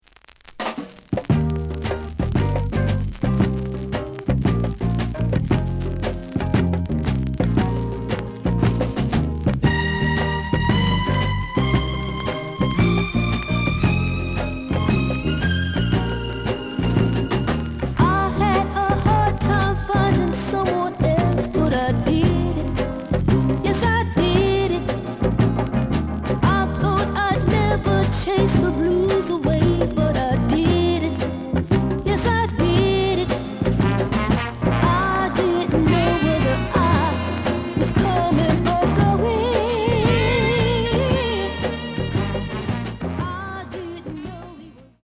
The audio sample is recorded from the actual item.
●Genre: Soul, 70's Soul
Some click noise on B side due to scratches.)